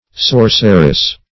Search Result for " sorceress" : Wordnet 3.0 NOUN (1) 1. a woman sorcerer ; The Collaborative International Dictionary of English v.0.48: Sorceress \Sor"cer*ess\, n. A female sorcerer.
sorceress.mp3